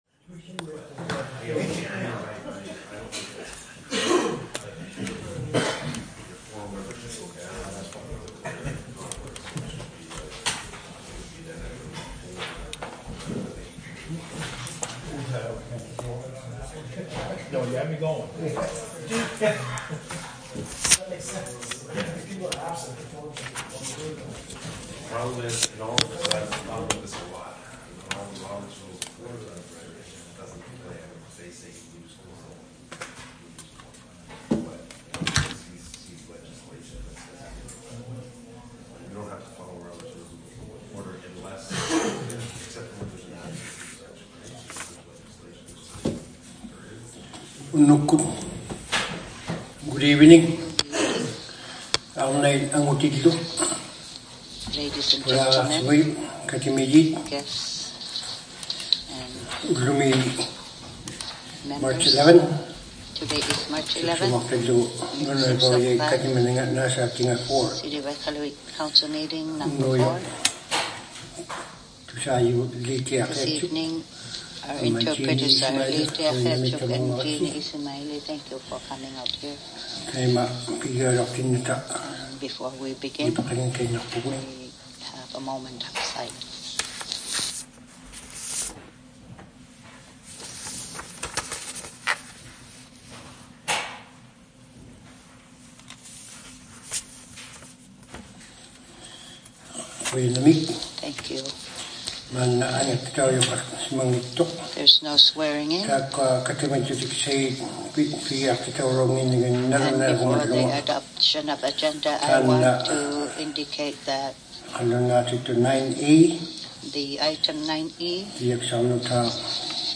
City Council Meeting #04 | City of Iqaluit
Due to technical issues with our AV system, the audio was recorded on a different device, which unfortunately does not allow us to separate the Inuktitut and English language tracks.